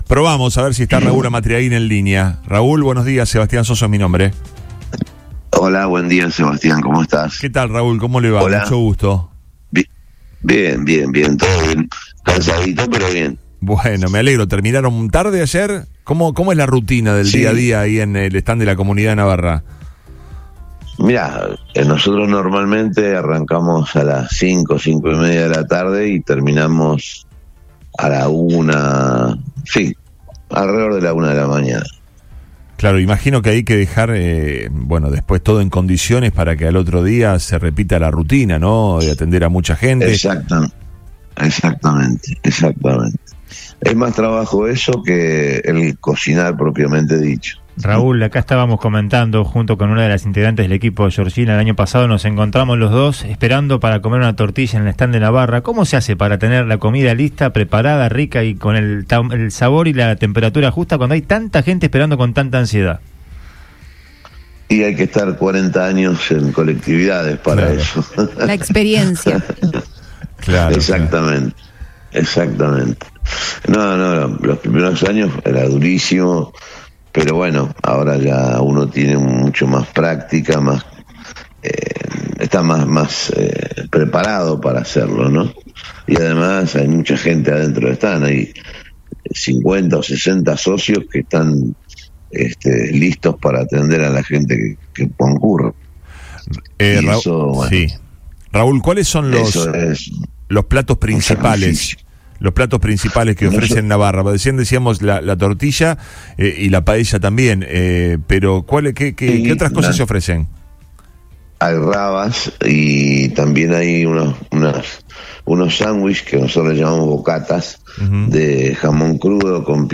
En diálogo con FM RÍO 96.9